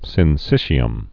(sĭn-sĭshē-əm)